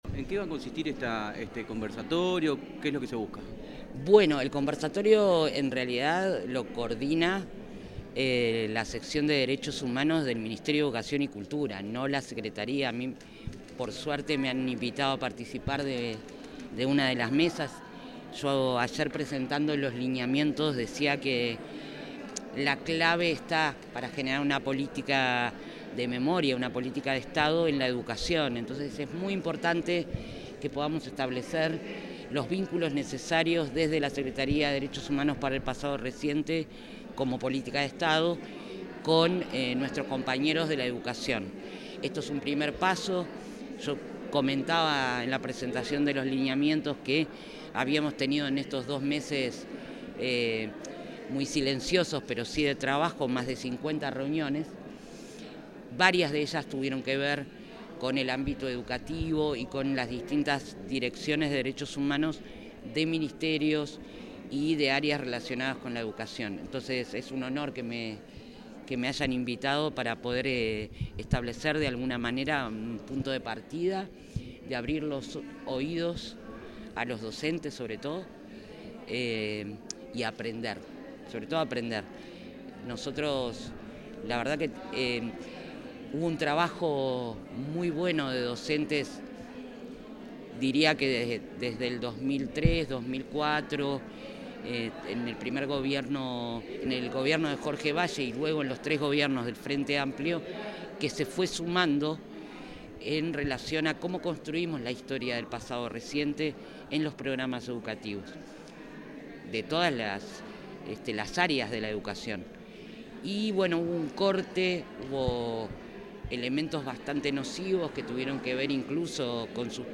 Declaraciones de la directora de la Secretaría de Derechos Humanos para el Pasado Reciente, Alejandra Casablanca
Antes, dialogó con la prensa.